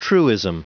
Prononciation du mot truism en anglais (fichier audio)
Prononciation du mot : truism